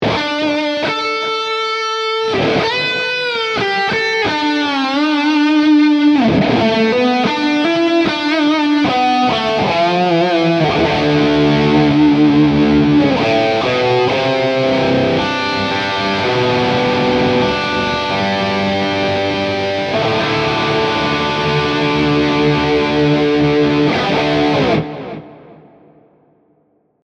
Tutte le clip audio, sono state registrate con testata a Marshall JCM800 sul canale Low e cassa 2×12 equipaggiata con altoparlanti Celestion Creamback, impostata su un suono estremamente clean.
Clip 3 – aggiunto Echo On Delay.
Chitarra: Fender Stratocaster (pickup al ponte)
Mode: Lead 1
Gain: 8/10